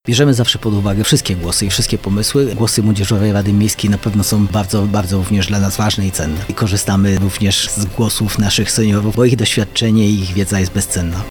Jak przyznaje burmistrz Szydłowca Artur Ludew są to organy prężnie działające: